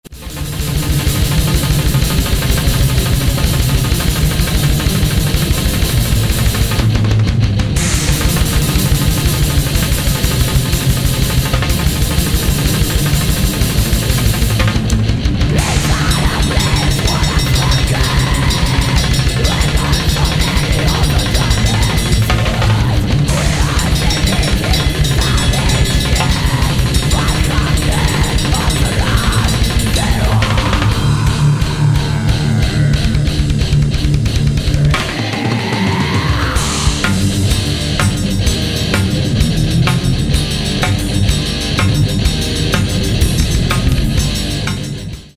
BLACK/DEATH